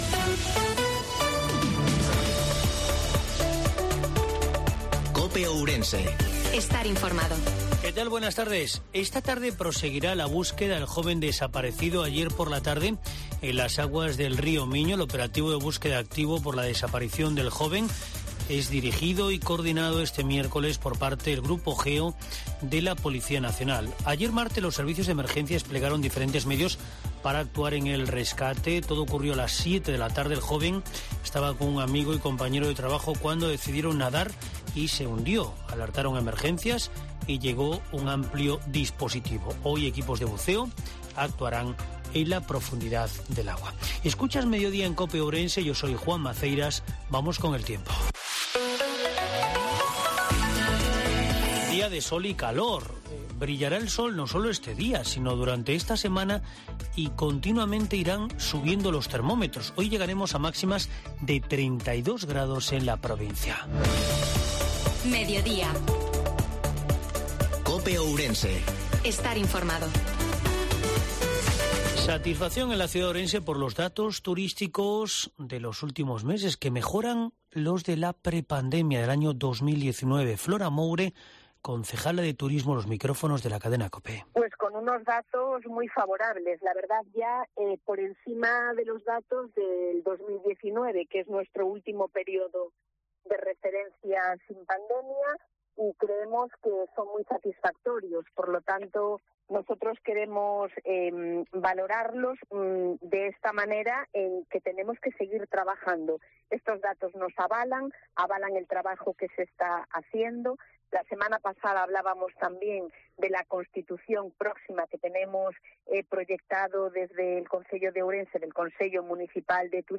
INFORMATIVO MEDIODIA COPE OURENSE. 06/07/22 14:20 H.